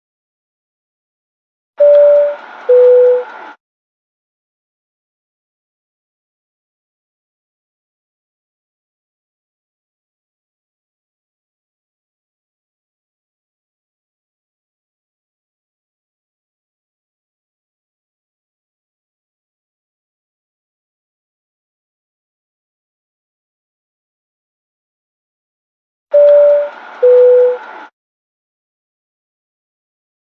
Pilot Announcement Sound effect sound effects free download